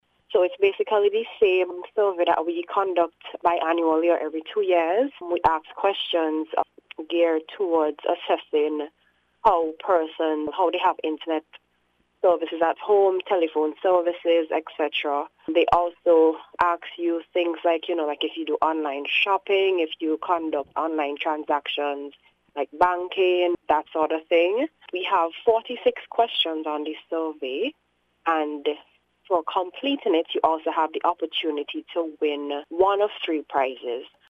In an interview with NBC News